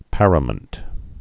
(părə-mənt)